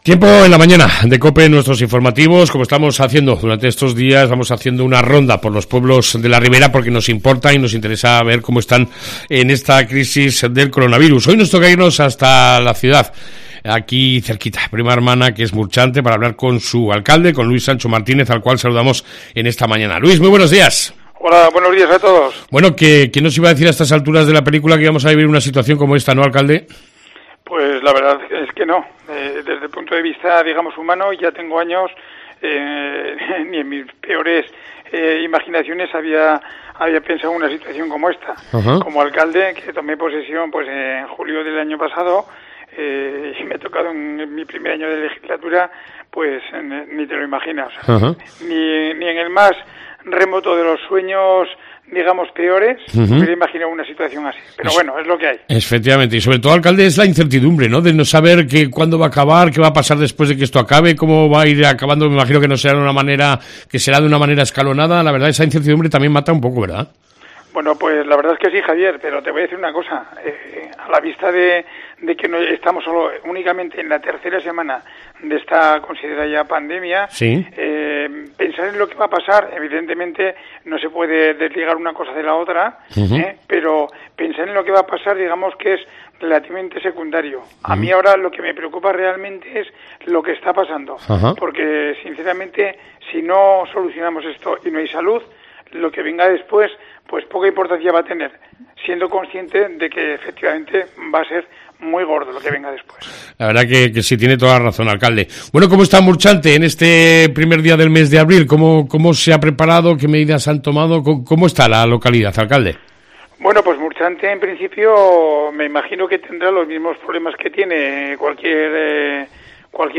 AUDIO: Hablamos de la última hora de Murchante, en esta crisis del Coronavirus, con su Alcalde Luís Sancho